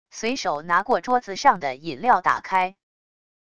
随手拿过桌子上的饮料打开wav音频